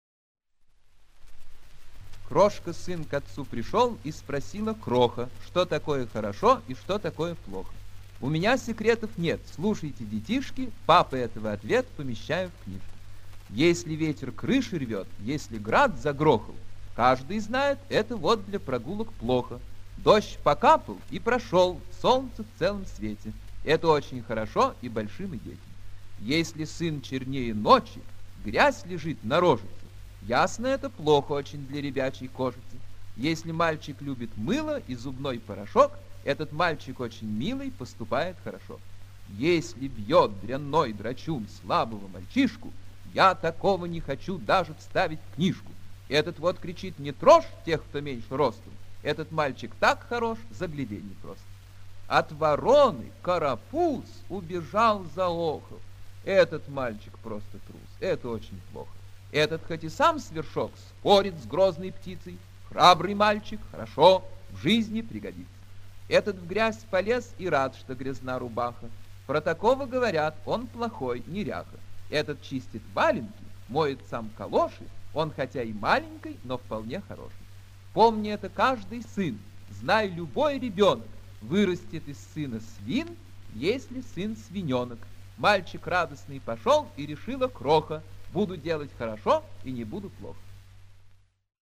15. «В. Н. Яхонтов читает стихи В. Маяковского – Что такое хорошо и что такое плохо?» /
N.-Yahontov-chitaet-stihi-V.-Mayakovskogo-CHto-takoe-horosho-i-chto-takoe-ploho-stih-club-ru.mp3